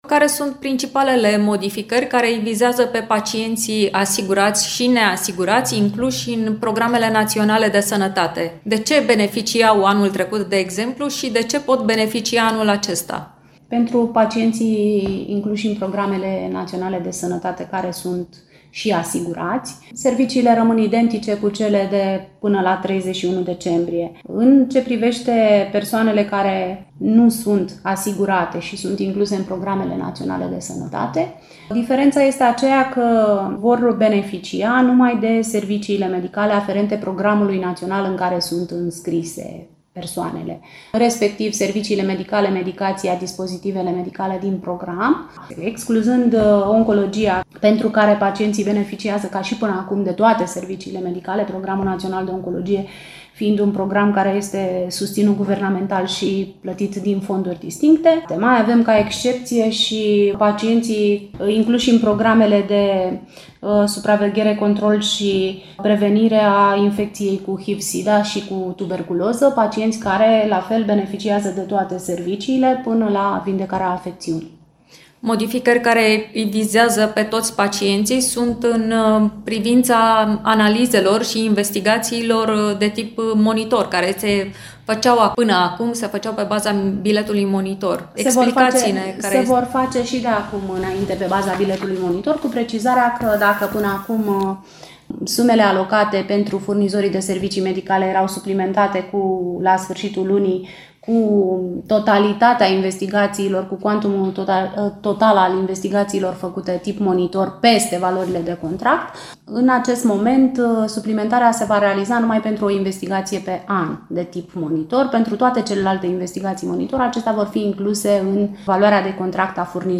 Care sunt categoriile care își păstrează prioritatea, ce se întâmplă cu neasigurații și dacă ne întoarcem la listele de așteptare pentru analize și investigații, aflăm de la Luminița Nagy, directorul Casei Județene de Asigurări de Sănătate Constanța.